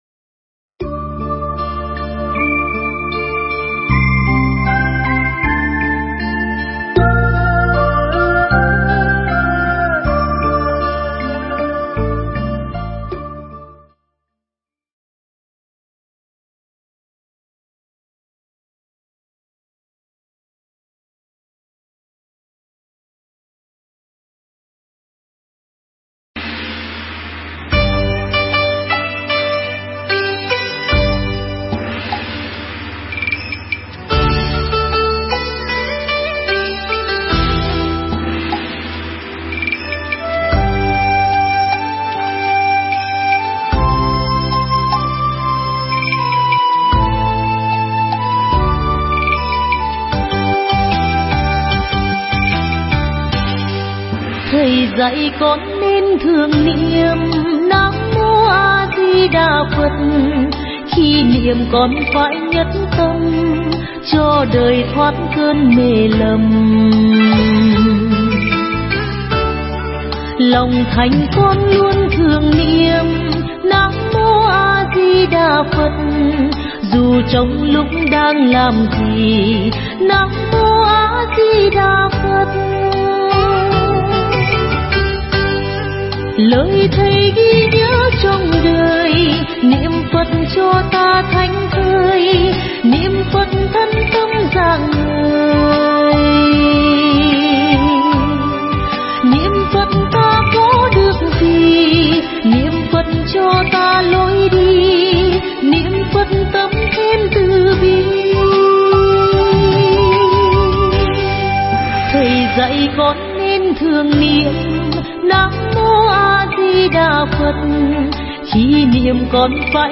Nghe Mp3 thuyết pháp Biết Lỗi Nên Sửa